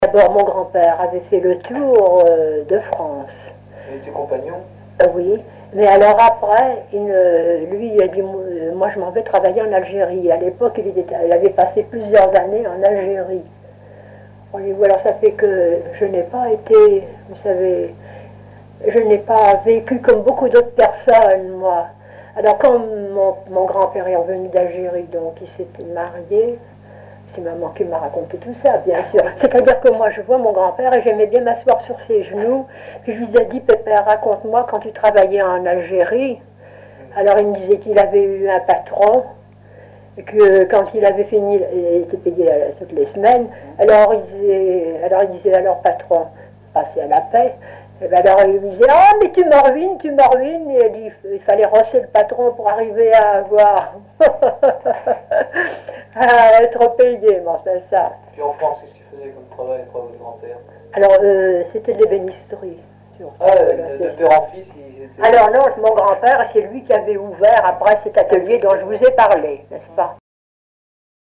Collectif chansons, témoignages
Catégorie Témoignage